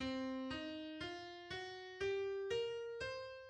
Blues scale
The blues scale is so named for its use of blue notes.